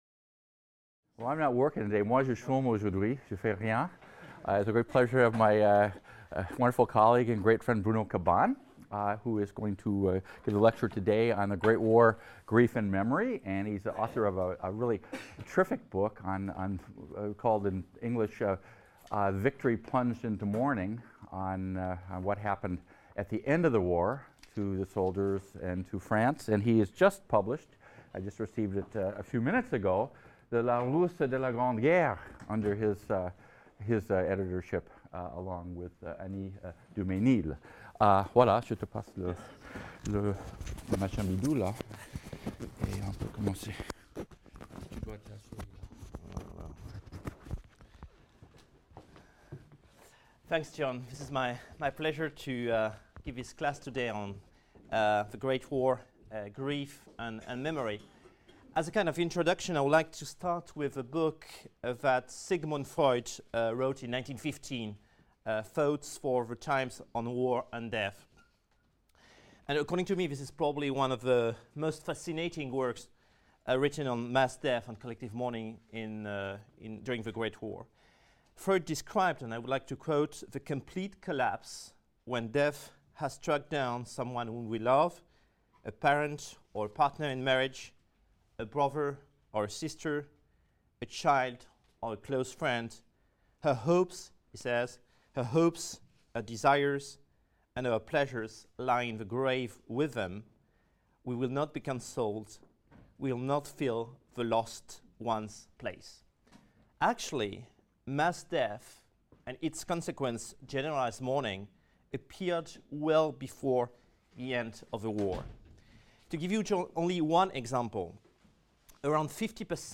HIST 276 - Lecture 16 - The Great War, Grief, and Memory